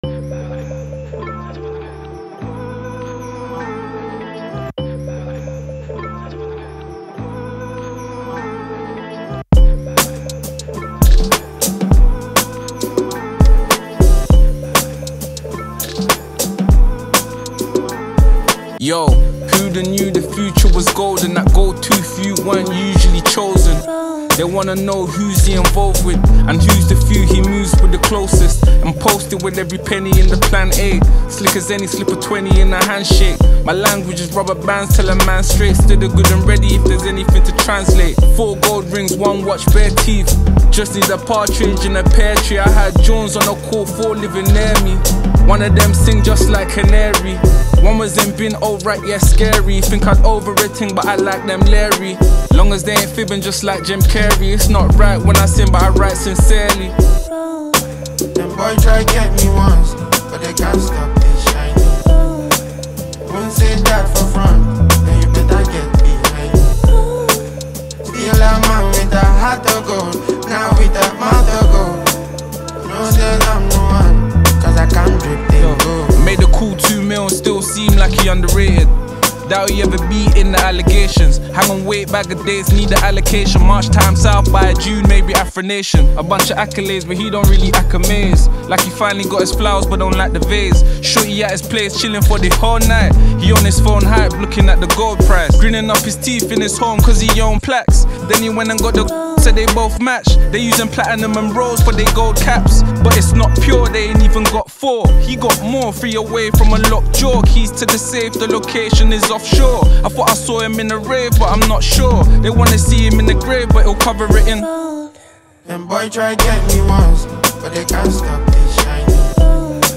” an interesting melody.